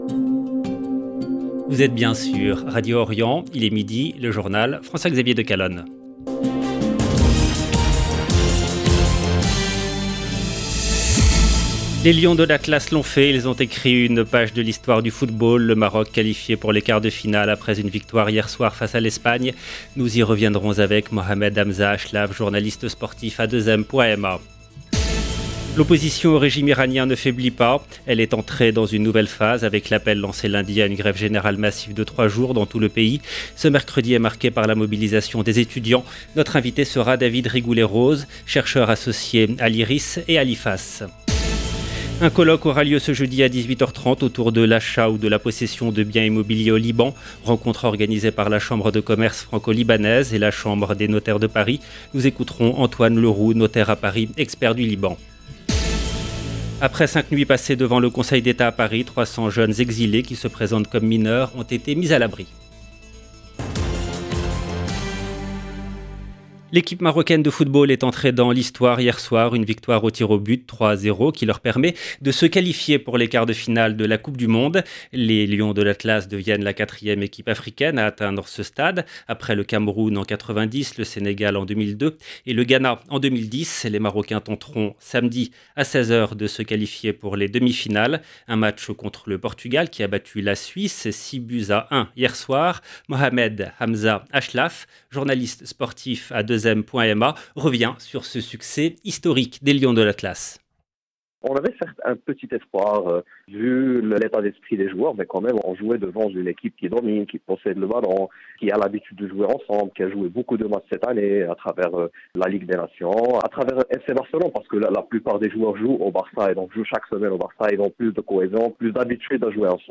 EDITION DU JOURNAL DE 12 H EN LANGUE FRANCAISE DU 7/12/2022